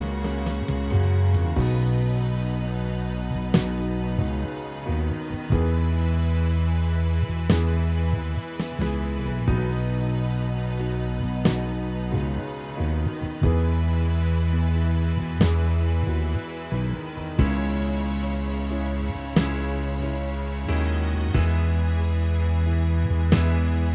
Minus Guitars Soft Rock 4:46 Buy £1.50